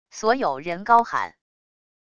所有人高喊wav音频